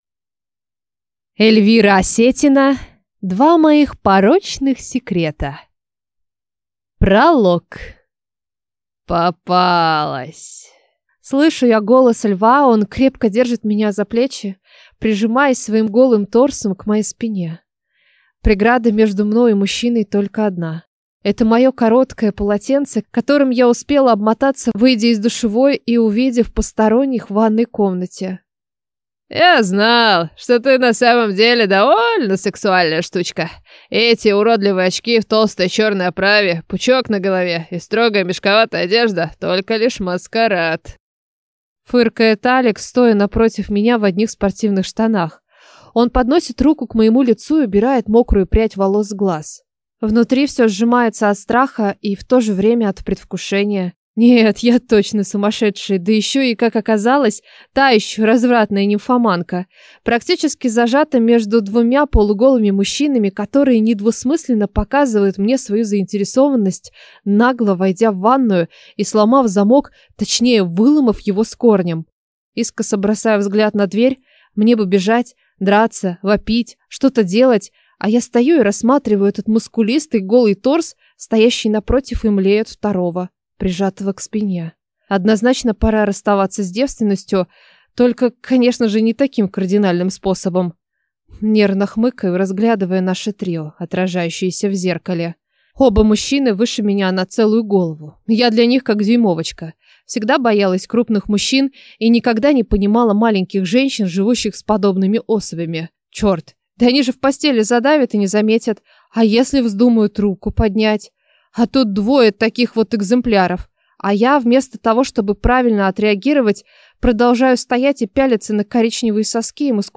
Аудиокнига Два моих порочных секрета | Библиотека аудиокниг